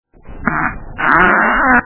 Screamer
Category: Sound FX   Right: Personal